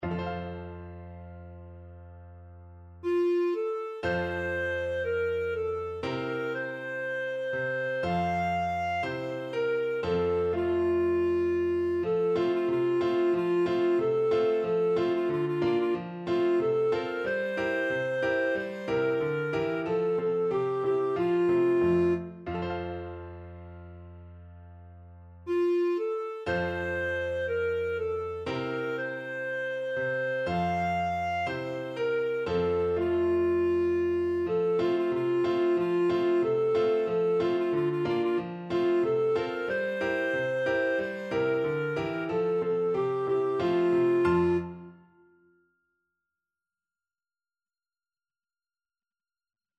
Christmas
Slow =c.60
2/2 (View more 2/2 Music)